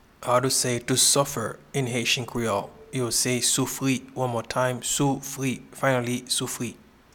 Pronunciation and Transcript:
to-Suffer-in-Haitian-Creole-Soufri-.mp3